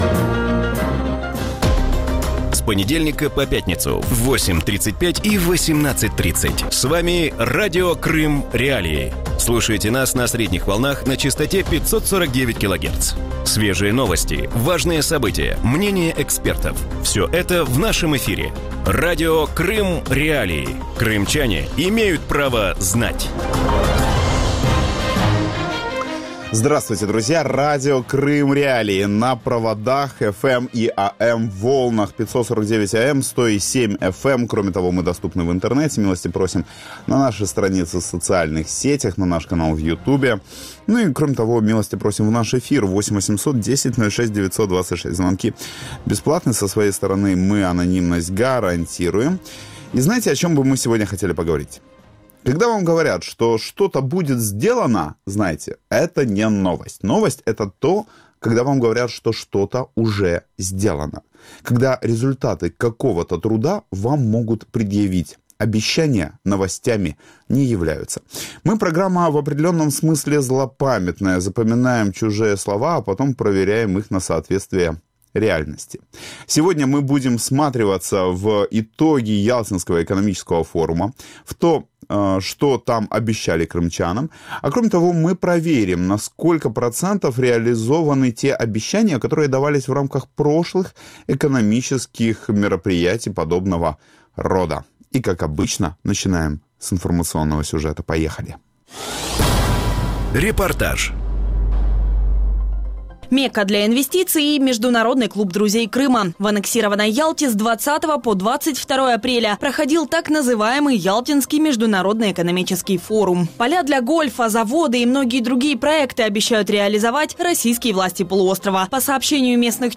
В вечернем эфире Радио Крым.Реалии обсуждают Ялтинский международный экономический форум.